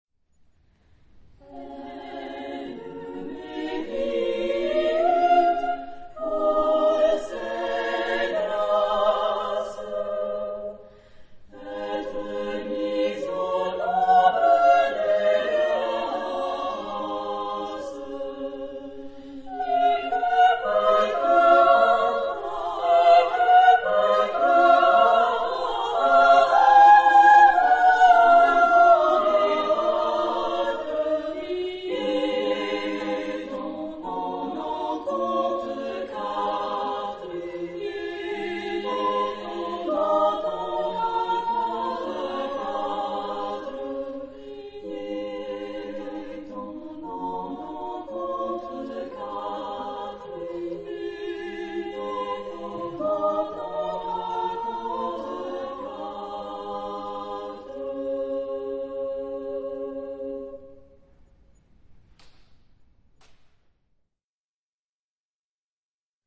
Género/Estilo/Forma: Profano ; Renacimiento ; Canción
Carácter de la pieza : grácil
Tipo de formación coral: SSAA  (4 voces iguales )
Tonalidad : re mayor
por Héliade, Sète
Ref. discográfica: Florilège Vocal de Tours, 2005